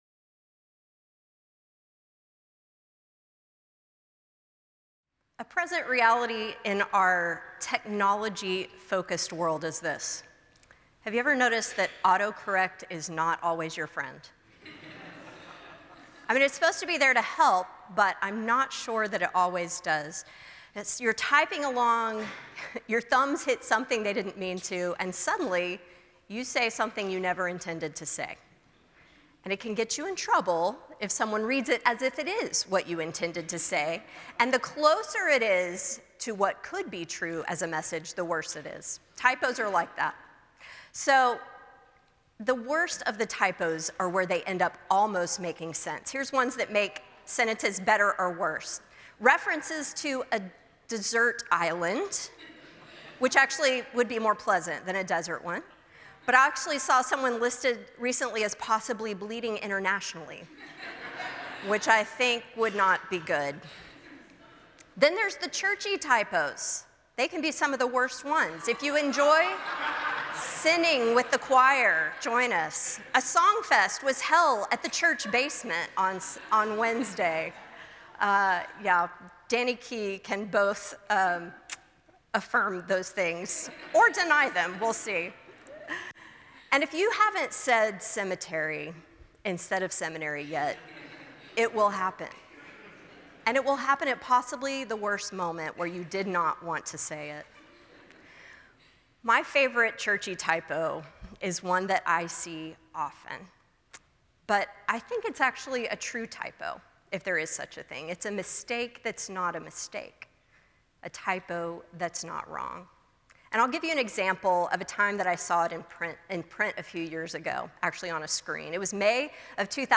The following service took place on Wednesday, September 6, 2023.